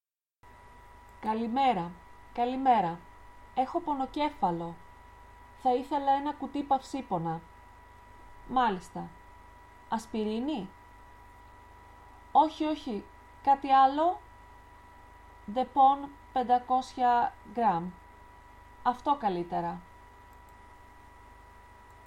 Dialog D: